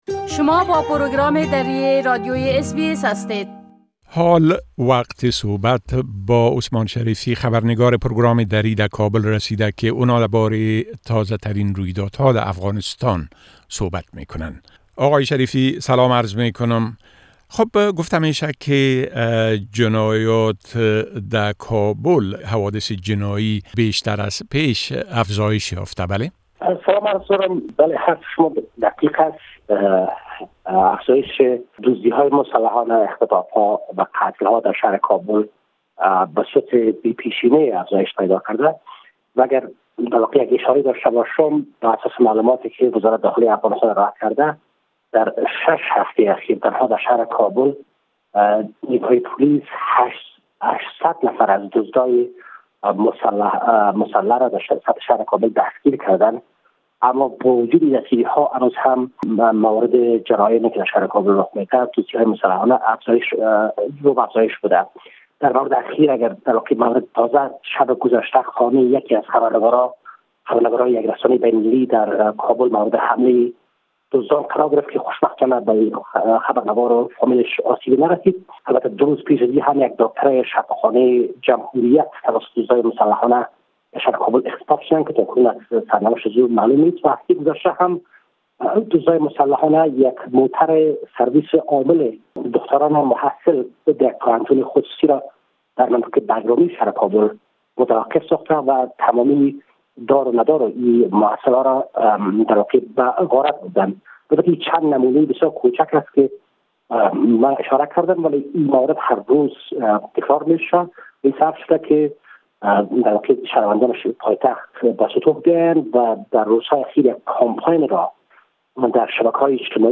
خبرنگار ما در کابل: جرايم جنائى در كابل بە پيمانۀ بى پيشينە افزايش يافتە.
گزارش كامل خبرنگار ما در كابل، به شمول اوضاع امنيتى و تحولات مهم ديگر در افغانستان، را در اينجا شنيده ميتوانيد.